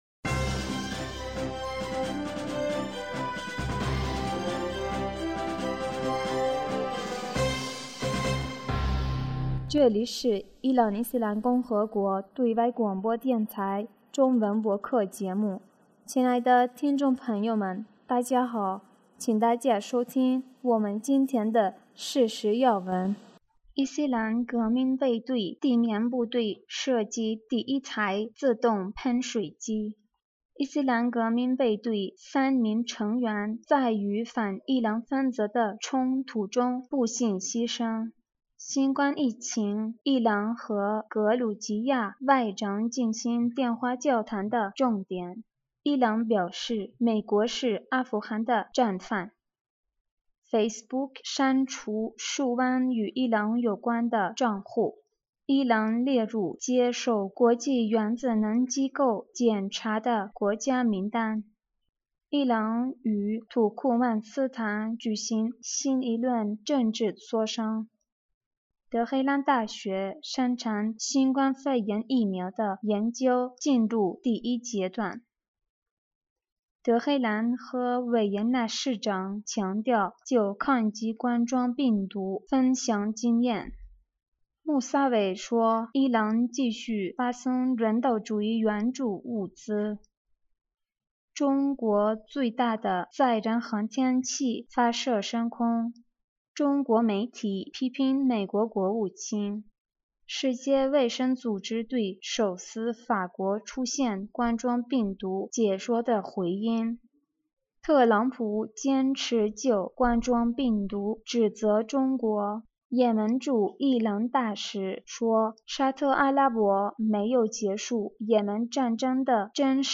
2020年5月6日 新闻
伊朗华语台消息：2020年5月6日 新闻